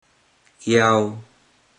Click each Romanised Teochew word to listen to how the Teochew word is pronounced.
iao3 (ace), not zeig1